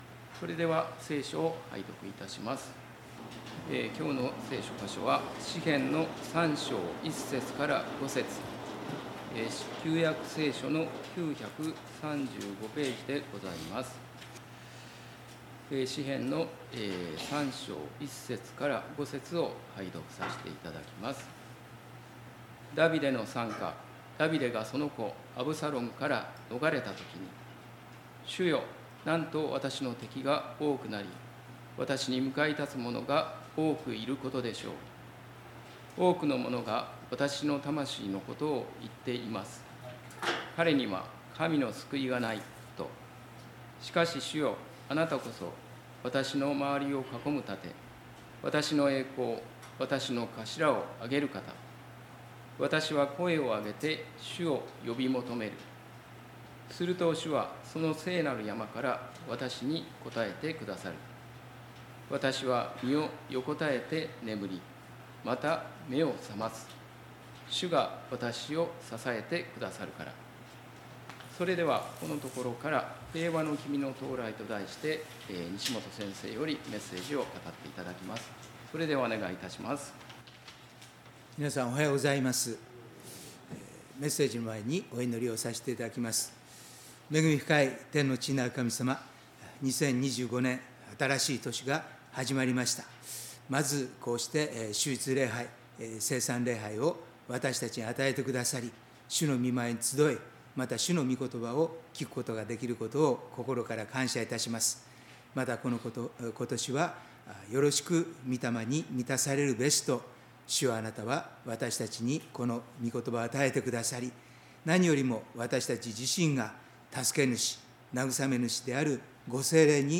礼拝メッセージ「生涯を満たす神」│日本イエス・キリスト教団 柏 原 教 会